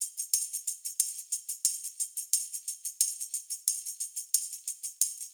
Index of /musicradar/sampled-funk-soul-samples/90bpm/Beats
SSF_TambProc2_90-01.wav